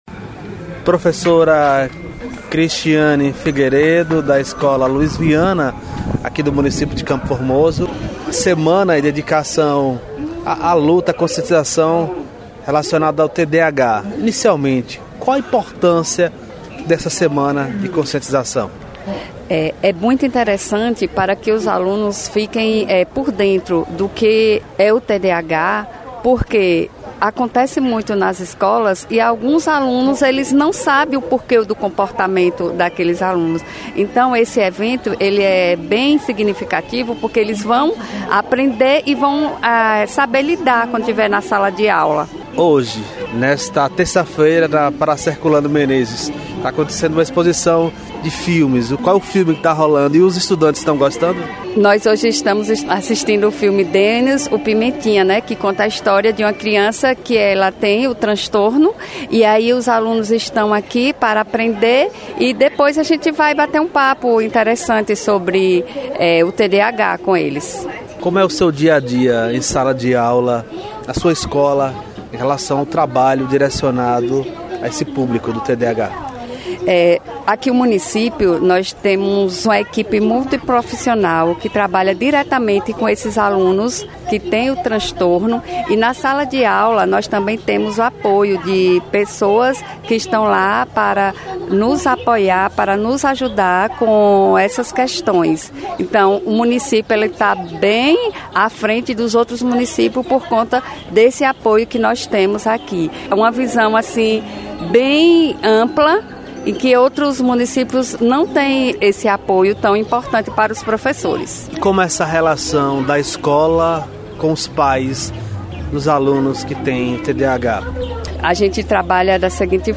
Entrevista com as professoras